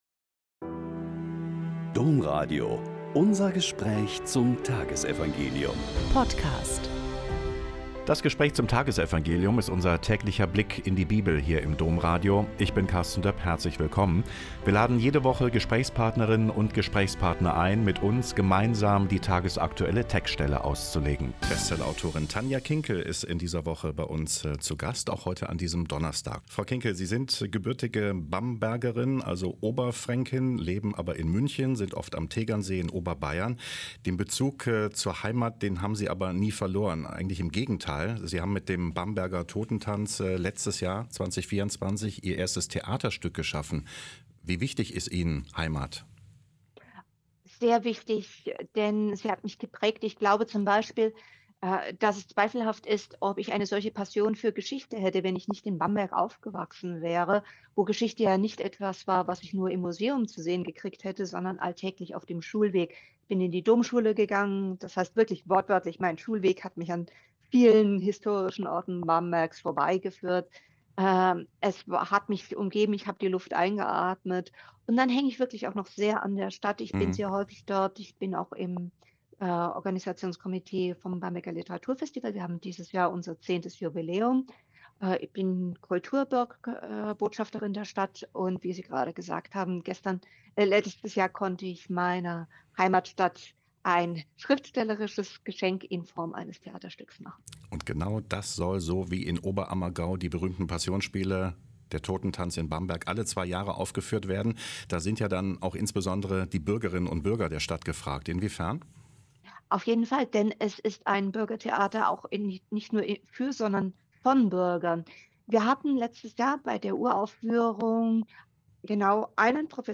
Joh 1,19-28 - Gespräch mit Tanja Kinkel